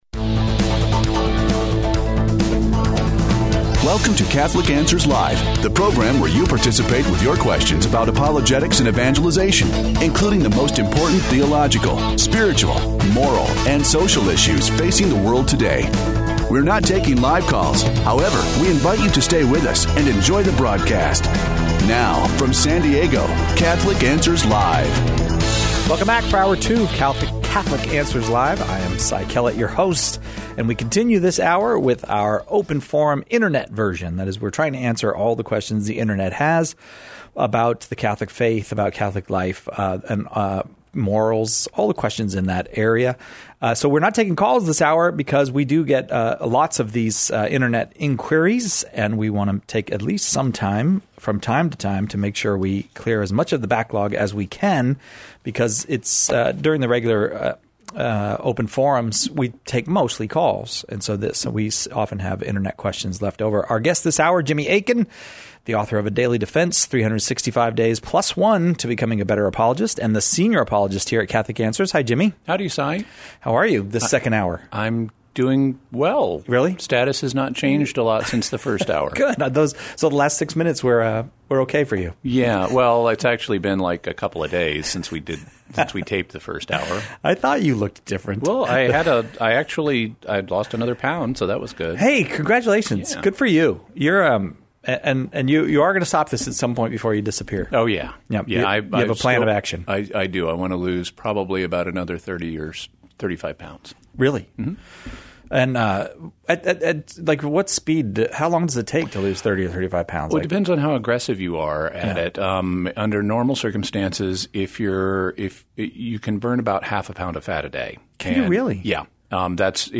Open Forum (Pre-recorded)